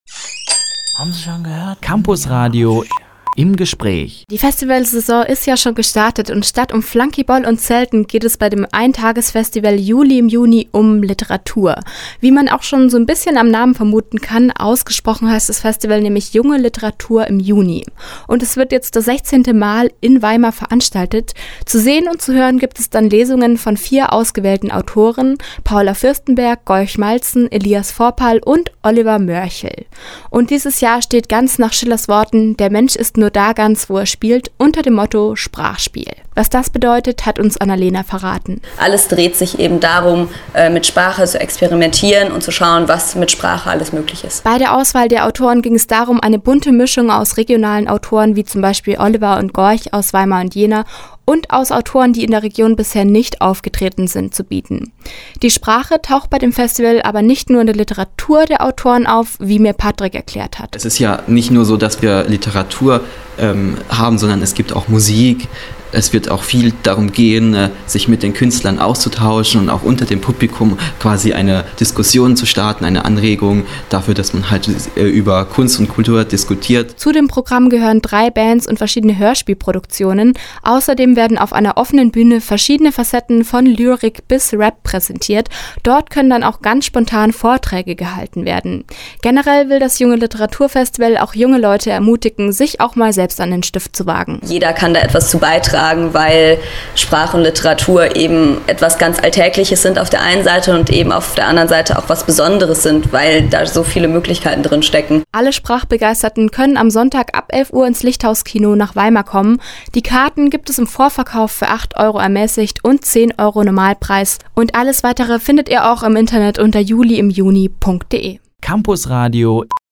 Im Gespräch: JuLi im Juni - Campusradio Jena